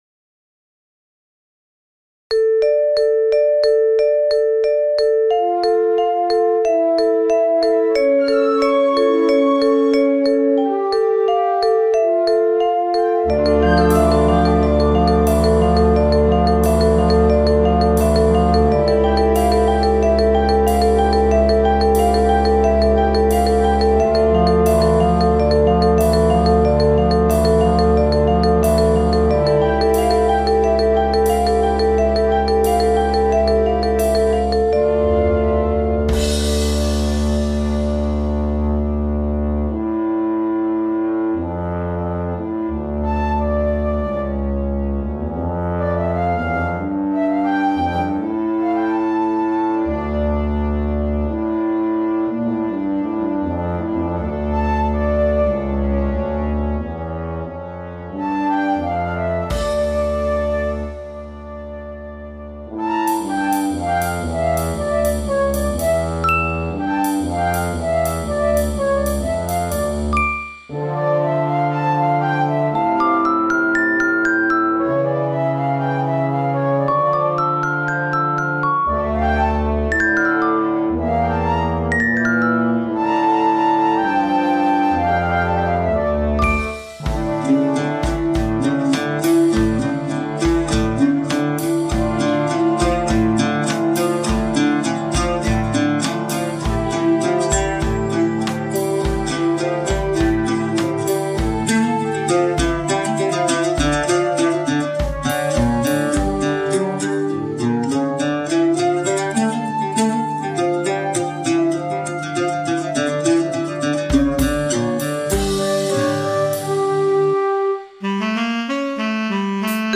Maar hun vrolijkheid verandert langzaam in een ruzie; ritmes botsen, stemmen stijgen, en chaos vult de kamer. Dan klinkt de diepe, kalme stem van de tuba de grootvader die met rustige wijsheid orde brengt.
Uit die stilte stijgt de fluit, licht en nieuwsgierig met een vraag. De spanning groeit, maar dan verschijnt de oud warm, ritmisch en vol liefde als de grootmoeder die met haar melodie iedereen weer samenbrengt. Daarna klinkt de saxofoon, speels en expressief, alsof een ander kind een nieuw idee toevoegt. Langzaam ontstaat er harmonie: de stemmen vermengen zich, het huis vult zich met vrede en rust.